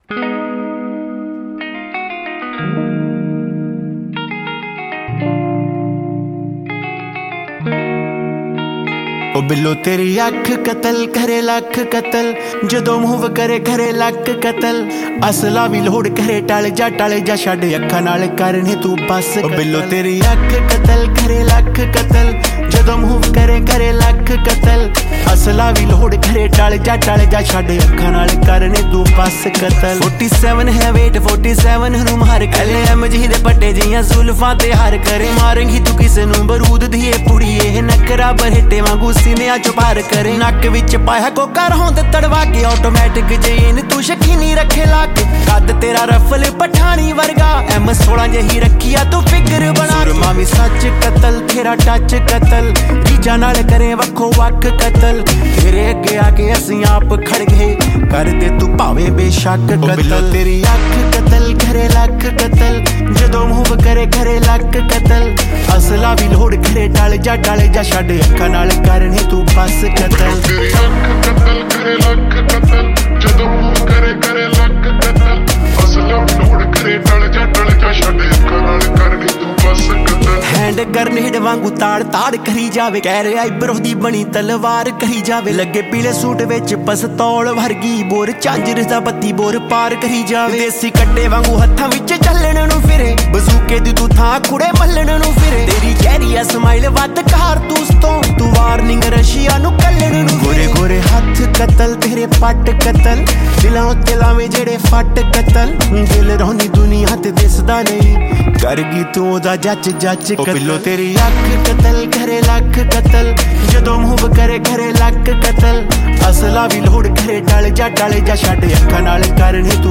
Releted Files Of Punjabi Music